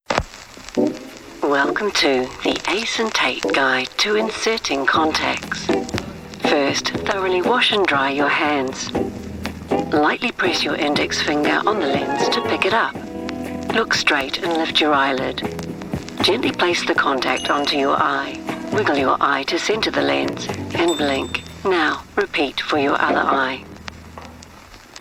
I have my own home studio and can deliver quickly.
My voice is described as warm, friendly, and authentic, perfect for a multitude of different genres I am confident in French, and German, offering versatility for international projects.